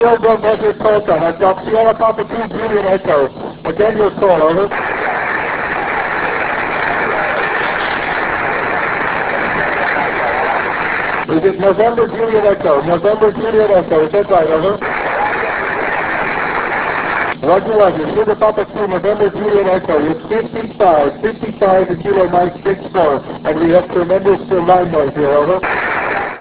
Although signal were relatively loud it was still a big struggle for us as the line noise was S7-9 at the time.